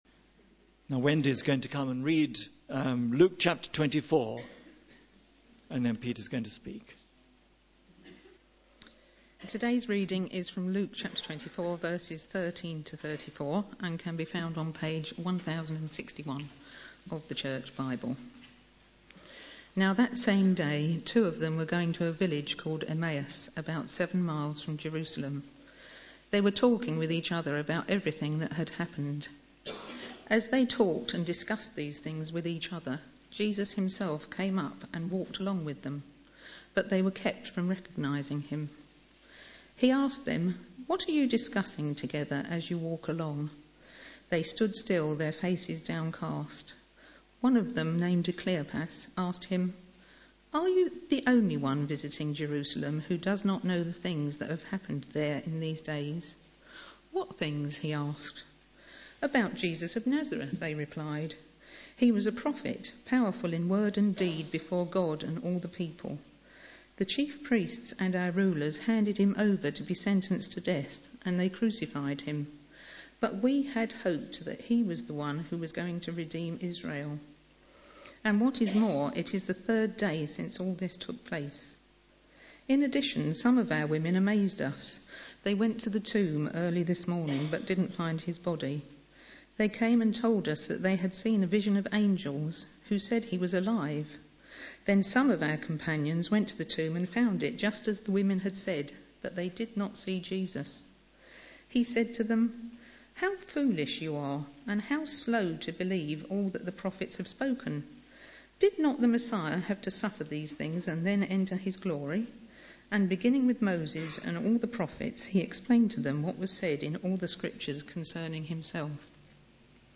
Sunday Service
The Road to Emmaus Sermon